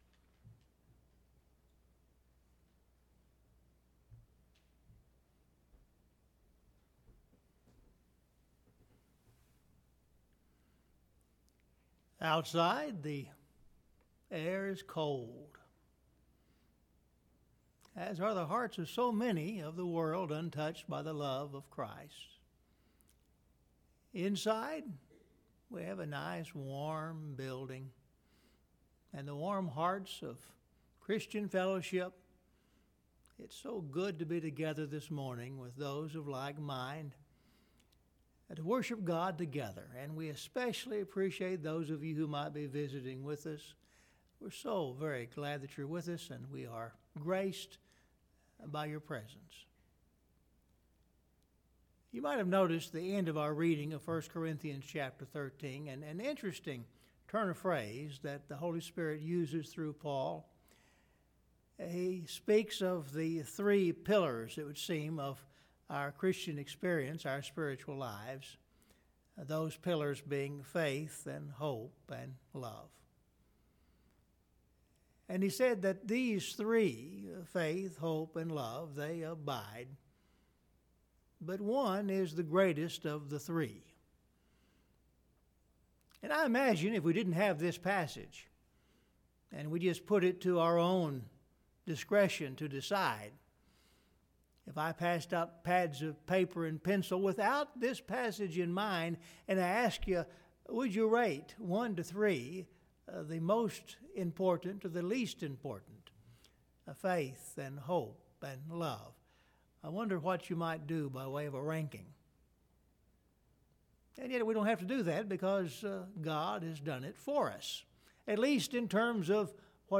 Scripture Reading – 1 Corinthians 13